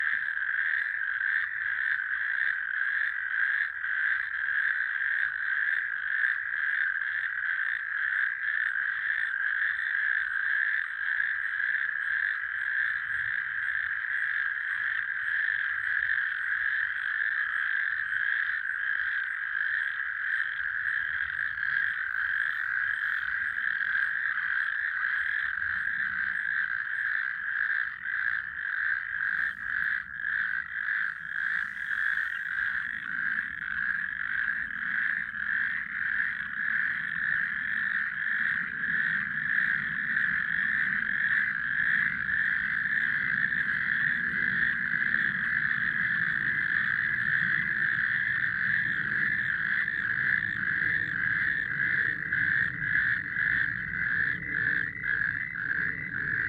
Heute Abend waren wir nicht im KKL in Luzern sondern beim KKB (Kreuzkrötenkonzert in Ballmoos).
Als wir bei Sonnenuntergang ankamen, ertönte schon ein mehrstimmiges Konzert aus dem hohen Gras neben dem Teich. Beim Einnachten füllte sich der Teich zunehmend mit Kreuzkröten.